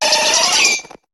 Cri d'Aéromite dans Pokémon HOME.